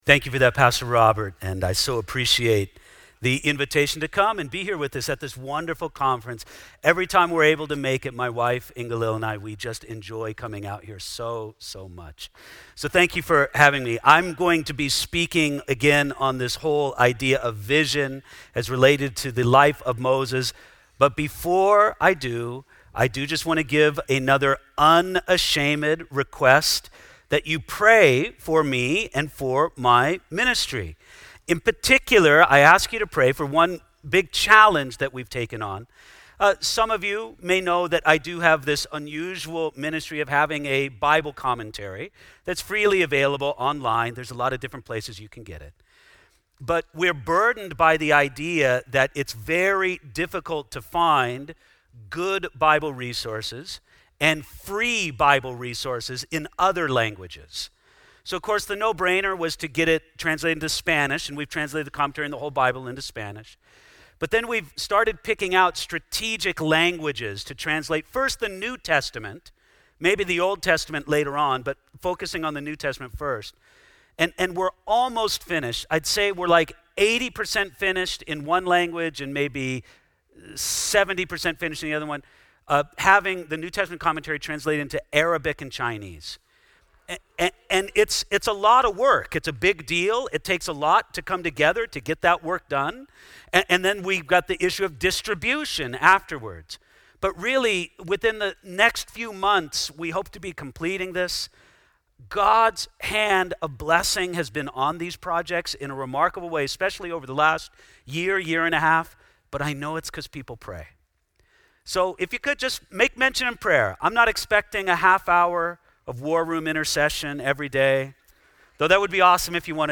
Southwest Pastors and Leaders Conference 2020